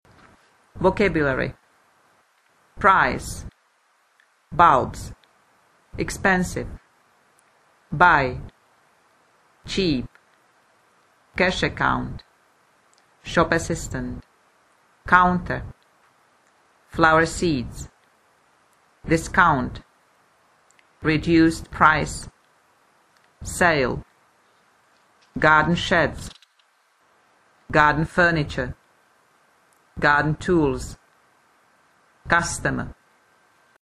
Nahrávka výslovnosti (*.MP3 soubory):